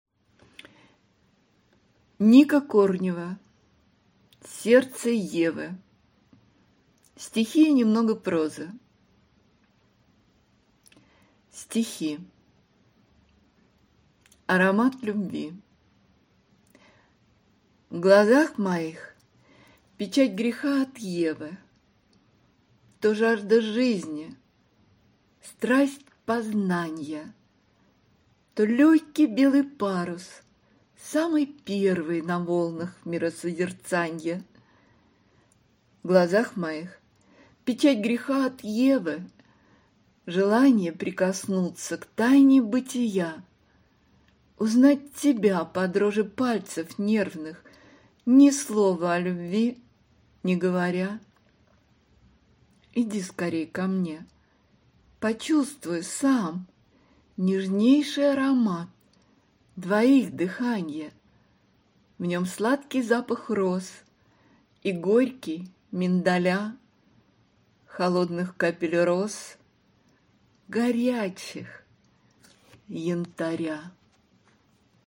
Аудиокнига Сердце Евы. Стихи и немного прозы | Библиотека аудиокниг